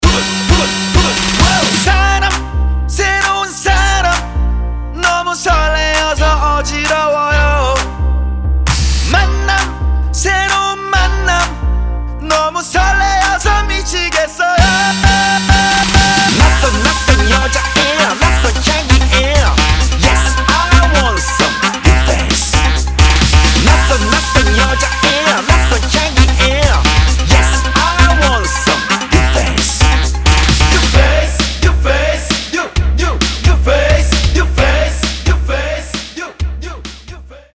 • Качество: 320, Stereo
громкие
Хип-хоп
dance
K-Pop